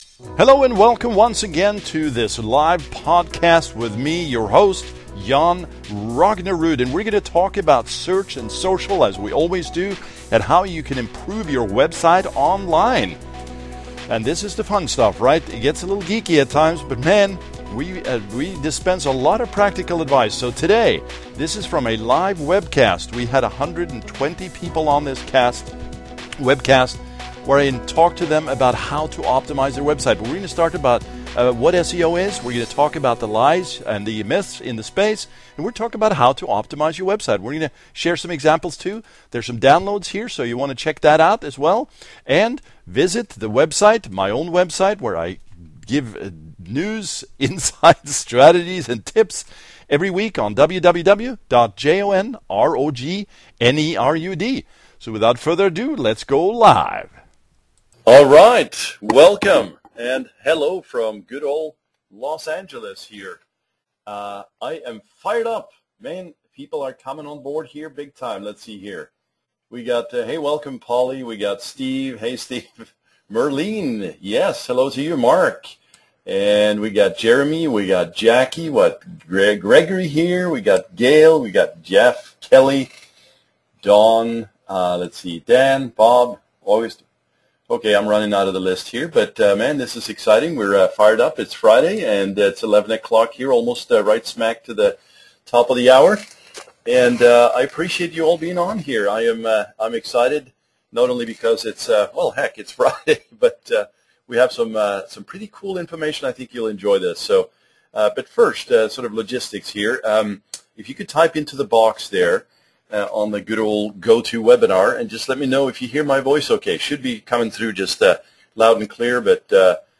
seo-webinar-optimizing-march2014-podcast.mp3